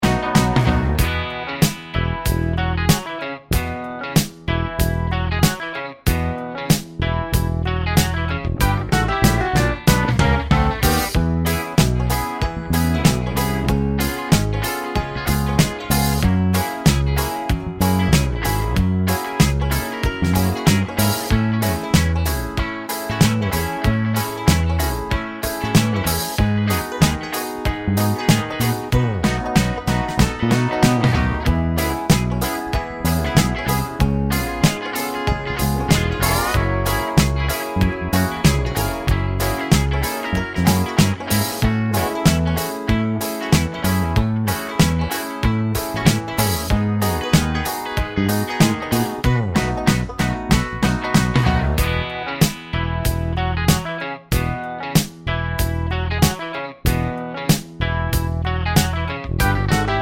no Backing Vocals Country (Male) 3:21 Buy £1.50